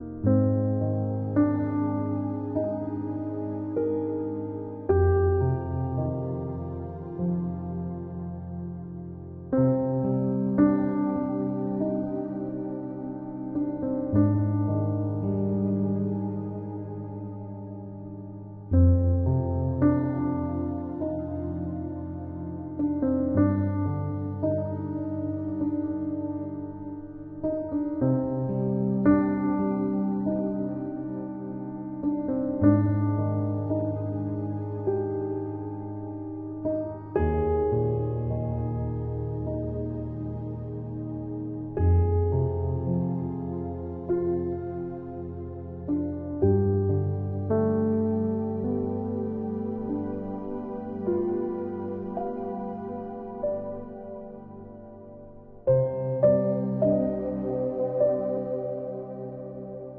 Deep Sleep